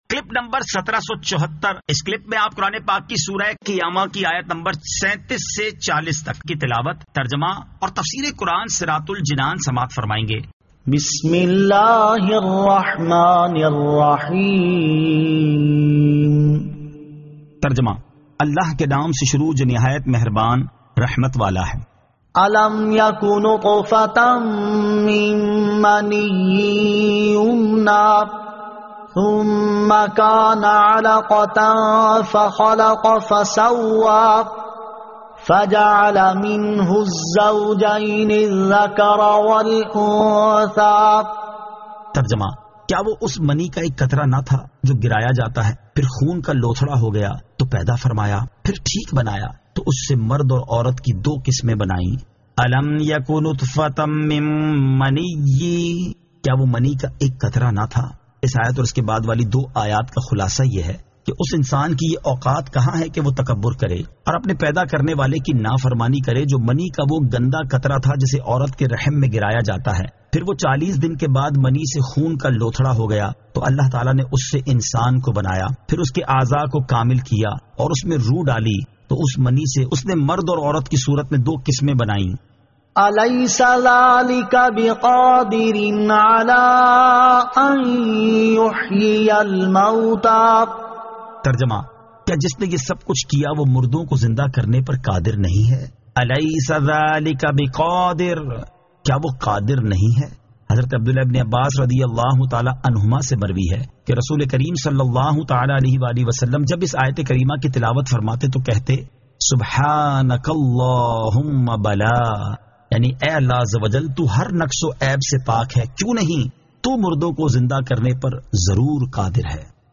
Surah Al-Qiyamah 37 To 40 Tilawat , Tarjama , Tafseer